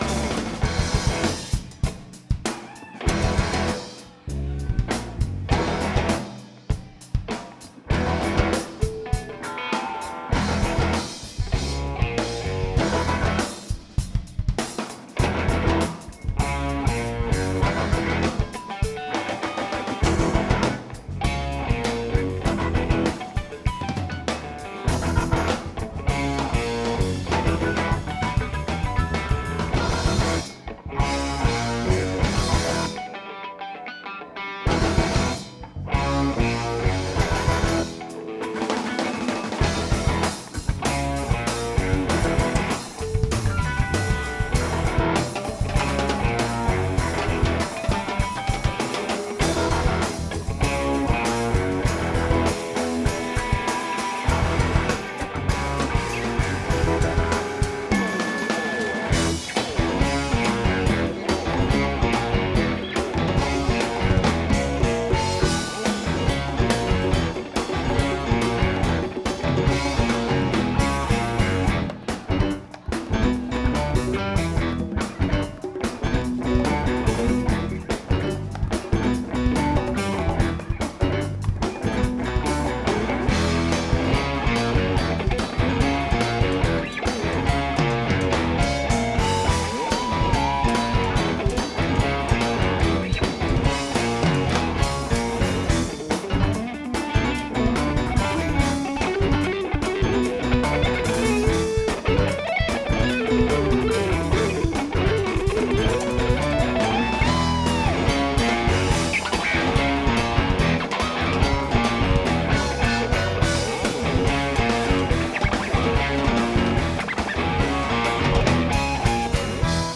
Smith Opera House - Geneva, NY, USA